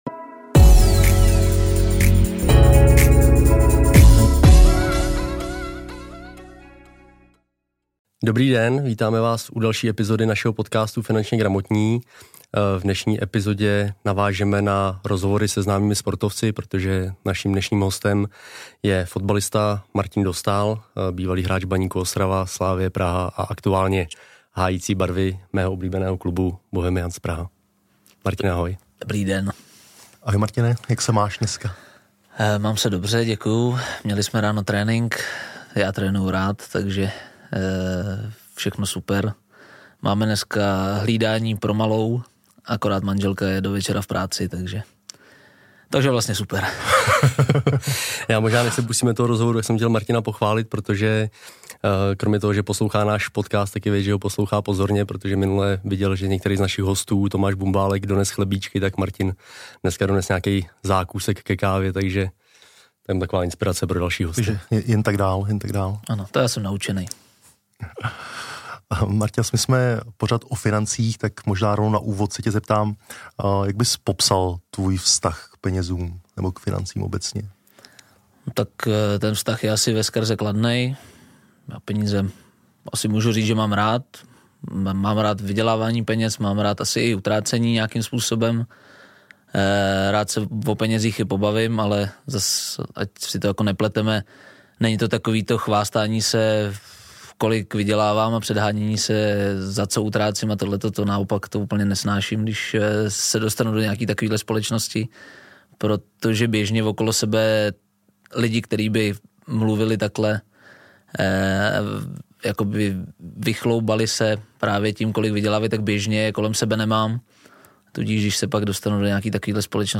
Náš rozhovor není ale jen o financích.